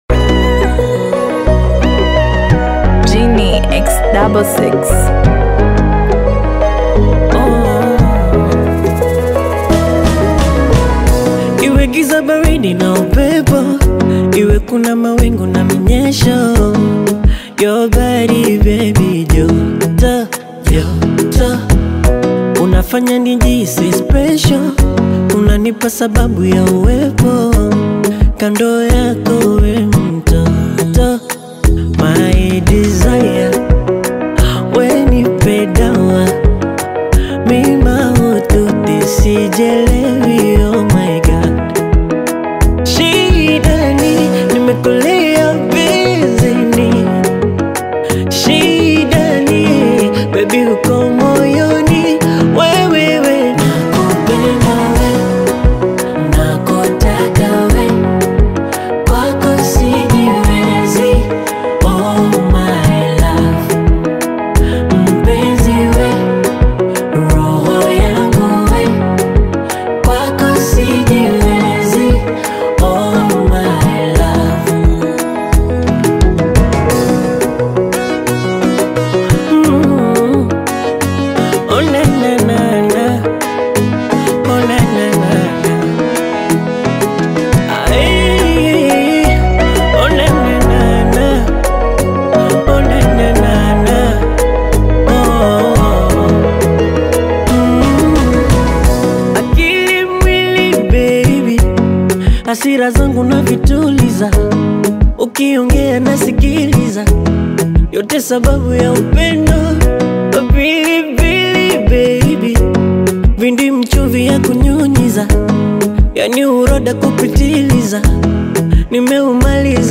Blessed trending lyrical gifted Tanzanian singer, songwriter